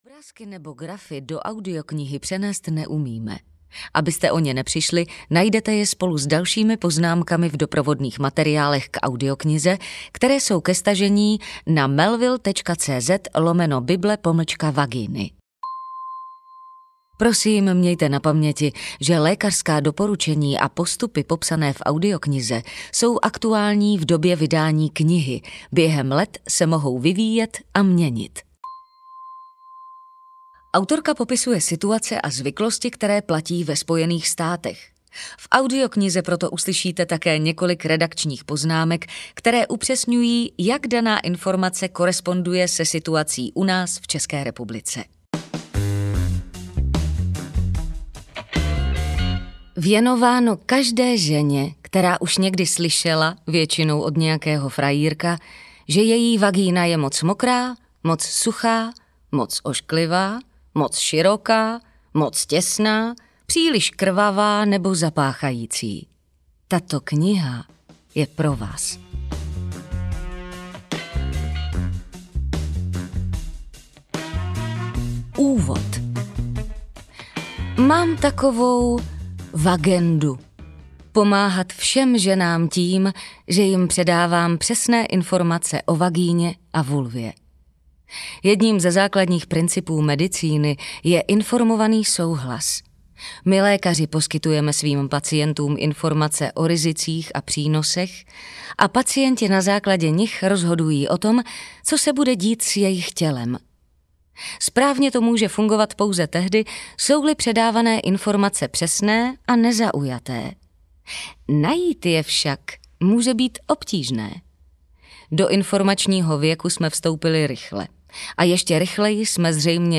Bible vagíny audiokniha
Ukázka z knihy